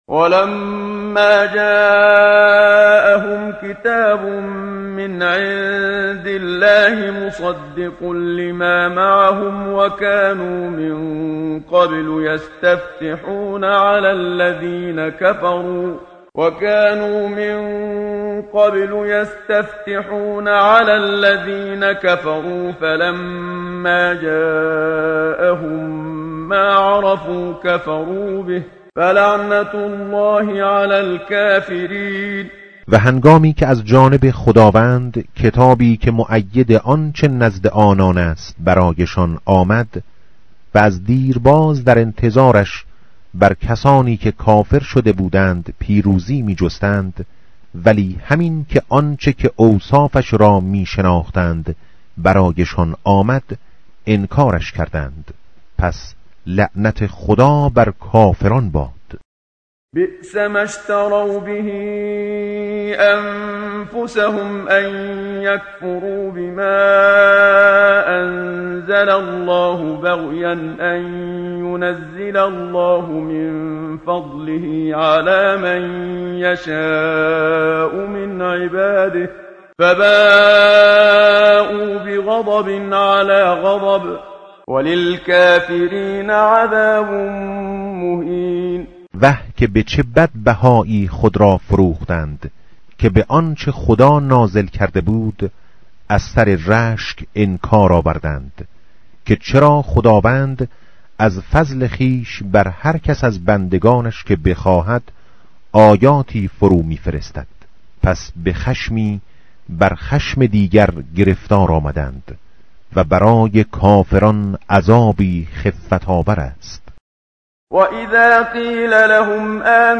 متن قرآن همراه باتلاوت قرآن و ترجمه
tartil_menshavi va tarjome_Page_014.mp3